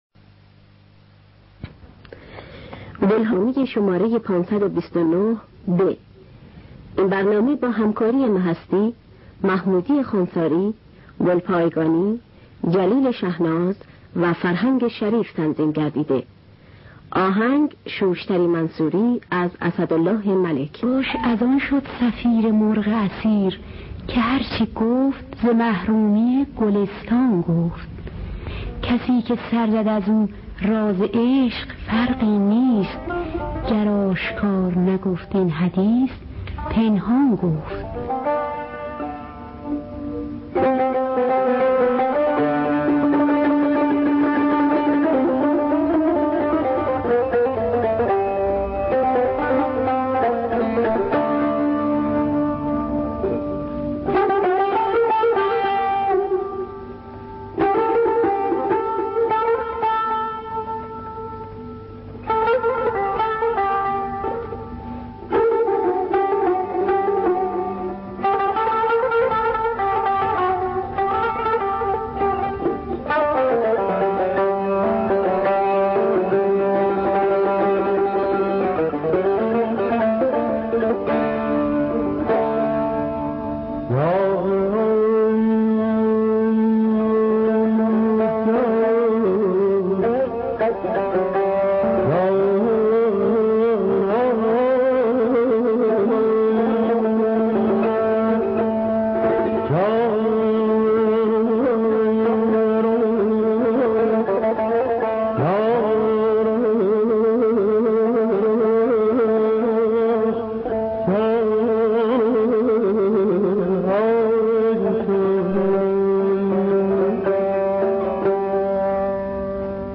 دانلود گلهای رنگارنگ ۵۲۹ب با صدای محمودی خوانساری، مهستی، اکبر گلپایگانی در دستگاه بیات اصفهان. آرشیو کامل برنامه‌های رادیو ایران با کیفیت بالا.